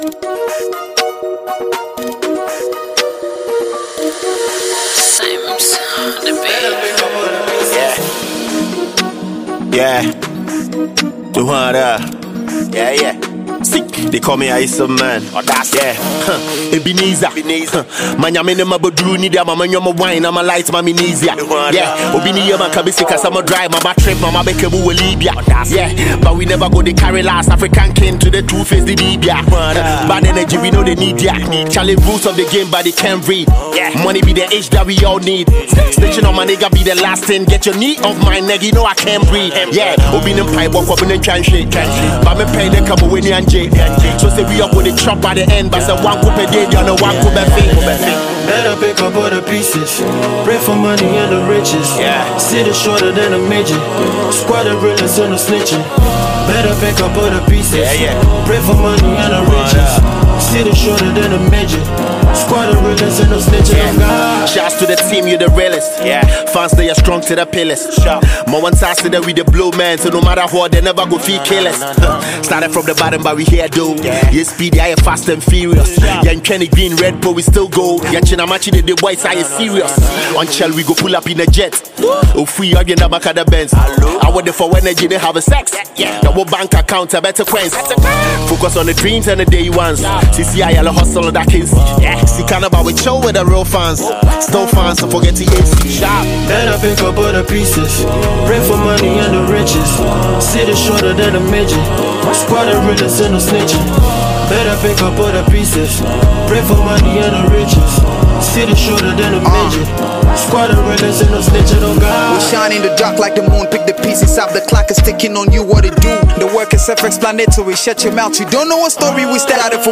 Ghana MusicMusic
Multi-talented Ghanaian rapper